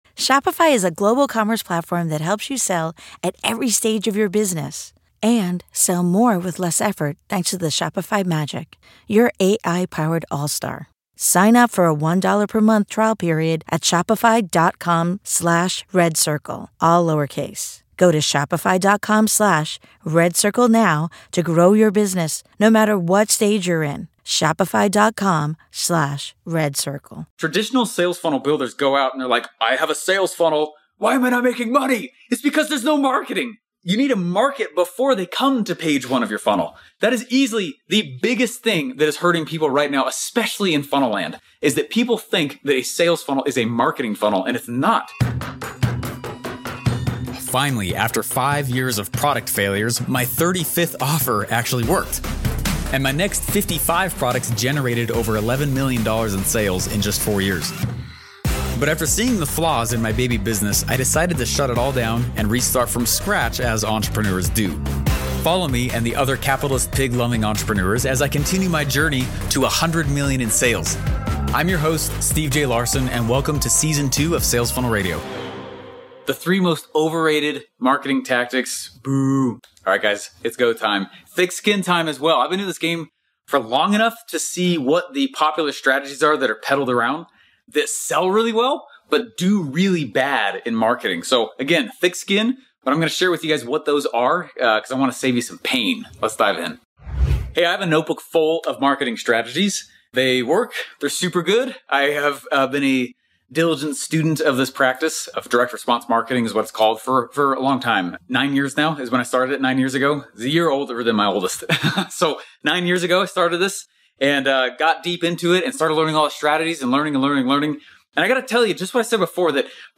In this episode, I took the audio from one of my YouTube videos to share with you what I think isn't worth your time but still very popular.